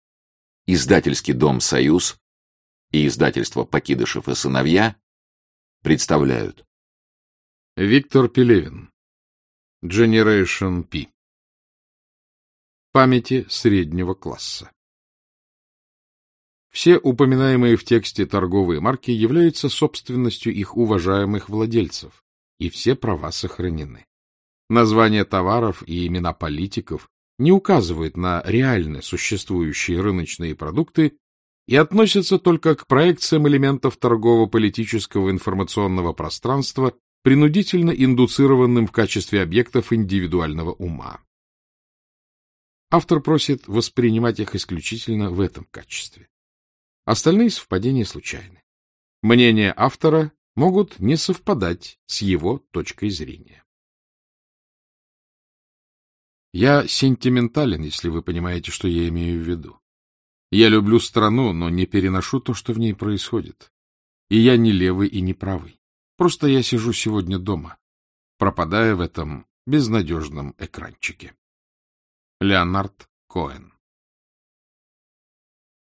Аудиокнига Generation П | Библиотека аудиокниг
Aудиокнига Generation П Автор Виктор Пелевин Читает аудиокнигу Александр Клюквин.